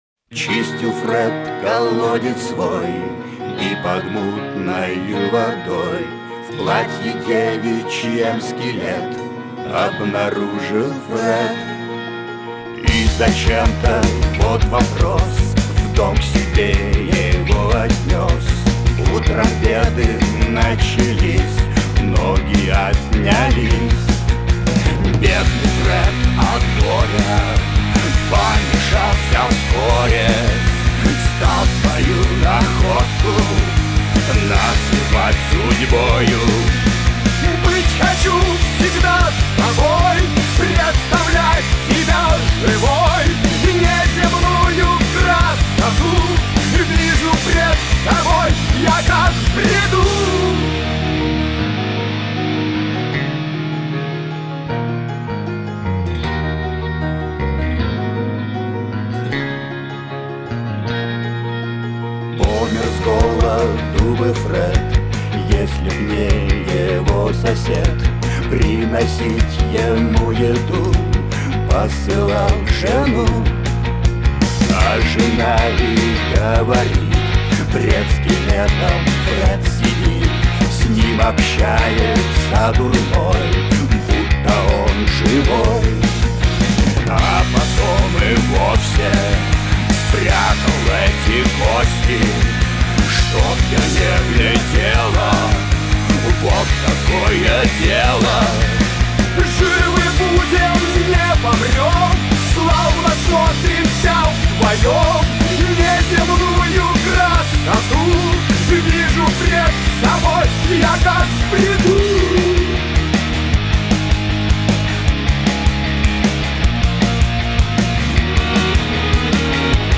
Категория: Рок